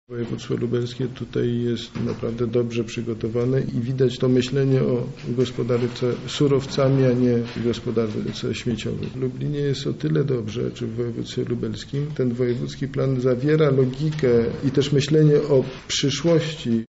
Dźwięk 1 – Minister Środowiska Marcin Korolec